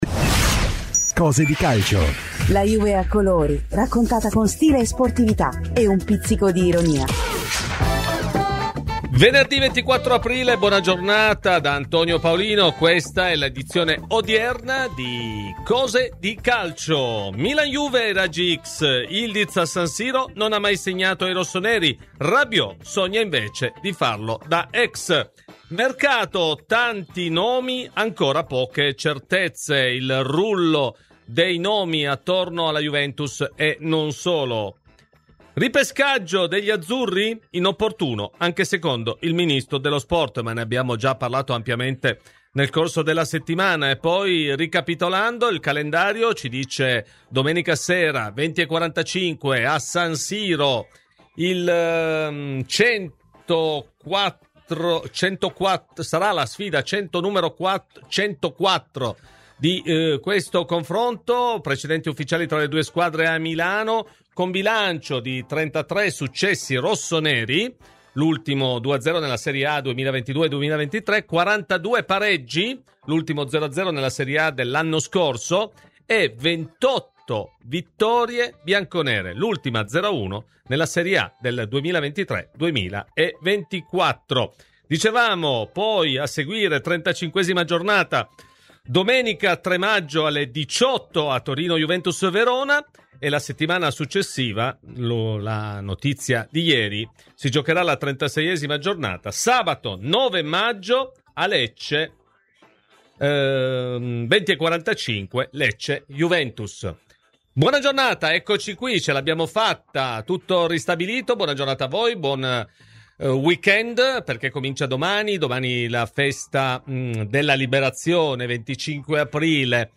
ospite oggi di “Cose di Calcio” su Radio Bianconera , ha parlato della sfida di domenica sera tra Milan e Juventus